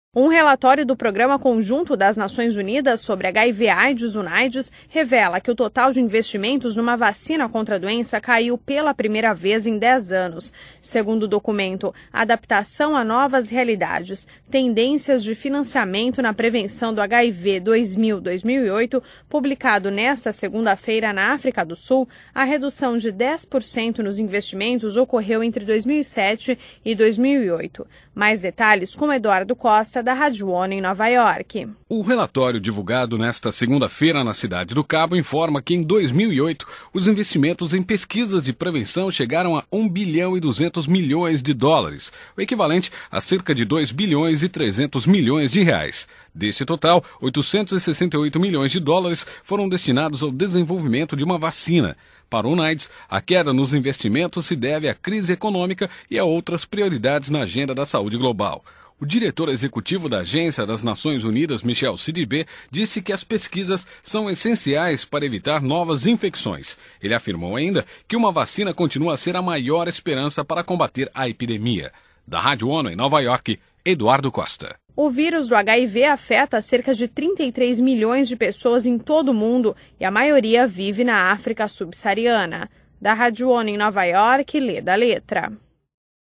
Fonte: Rádio das Nações Unidas